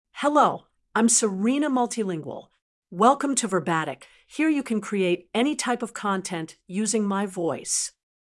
Serena MultilingualFemale English AI voice
Serena Multilingual is a female AI voice for English (United States).
Voice: Serena MultilingualGender: FemaleLanguage: English (United States)ID: serena-multilingual-en-us
Voice sample
Listen to Serena Multilingual's female English voice.
Serena Multilingual delivers clear pronunciation with authentic United States English intonation, making your content sound professionally produced.